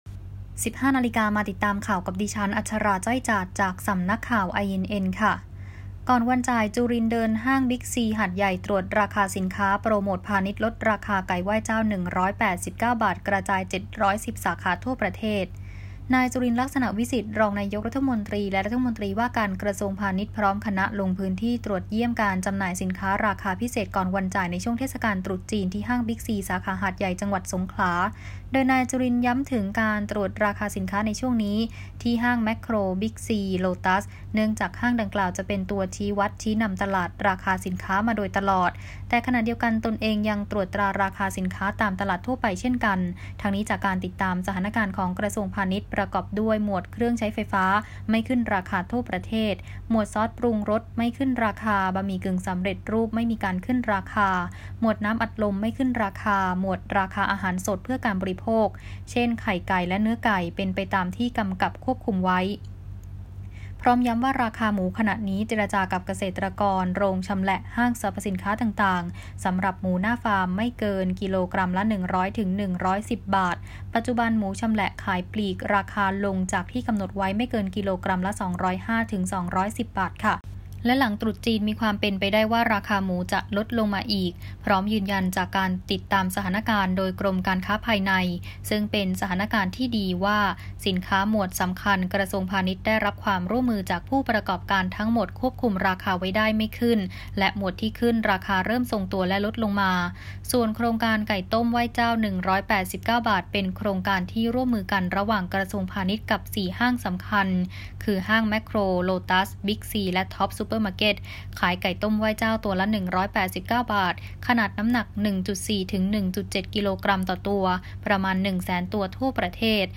คลิปข่าวต้นชั่วโมง